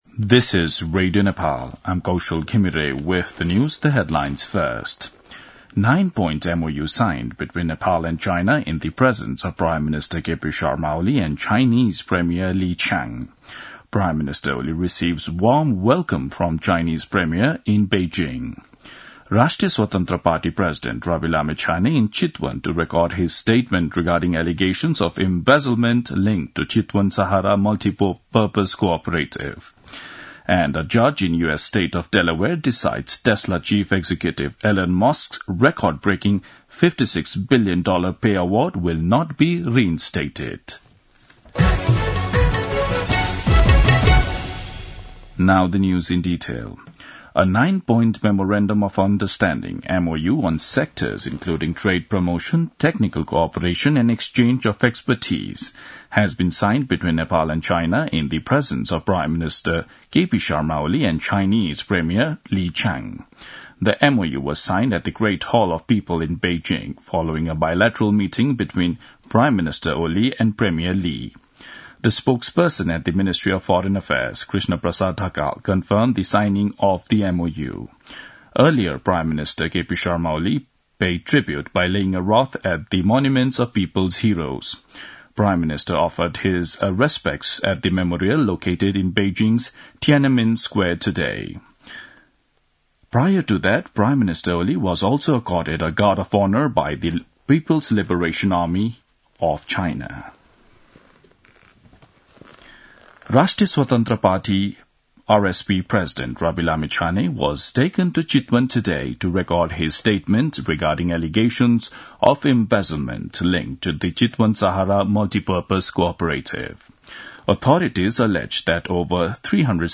दिउँसो २ बजेको अङ्ग्रेजी समाचार : १९ मंसिर , २०८१
2-pm-english-news-1-2.mp3